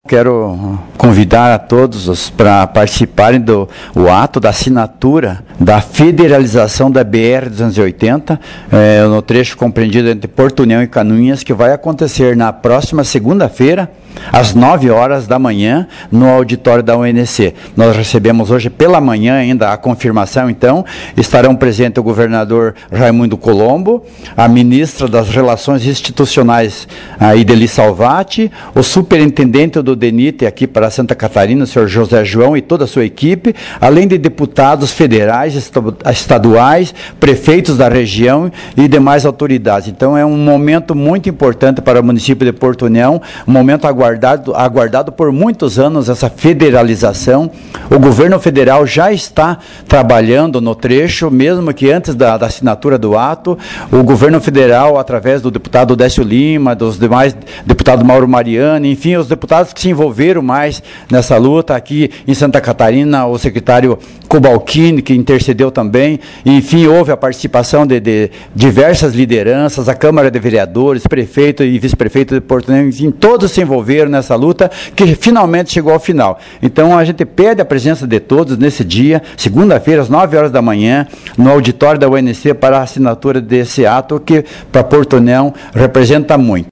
Na oportunidade o prefeito Anízio de Souza, falou sobre a expectativa da assinatura e as futuras obras para o melhoramento da rodovia.